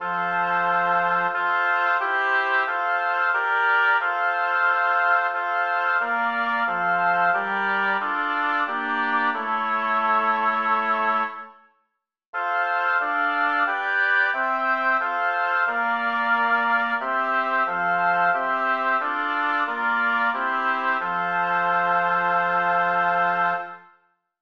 Title: Christus, der ist mein Leben b Composer: Melchior Vulpius Lyricist: Number of voices: 4vv Voicing: SSAT Genre: Sacred, Chorale
Language: German Instruments: A cappella